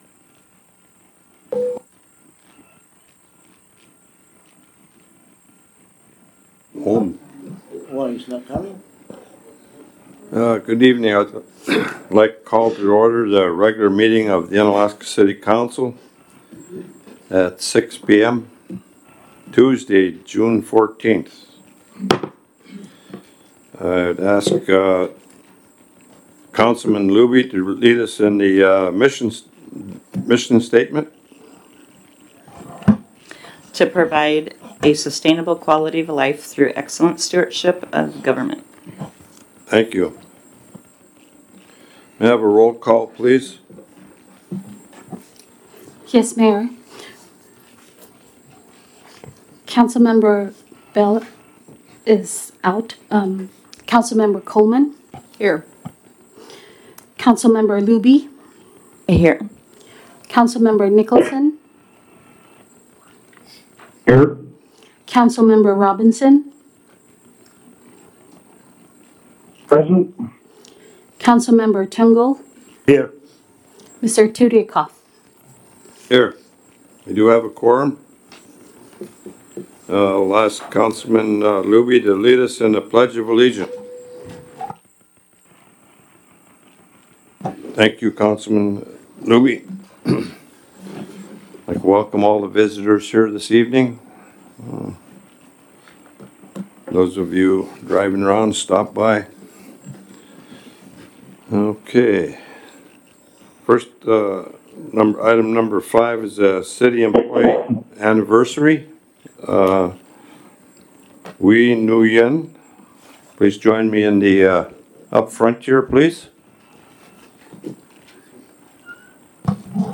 Council Meeting - June 14, 2022 | City of Unalaska - International Port of Dutch Harbor
In person at City Hall (43 Raven Way)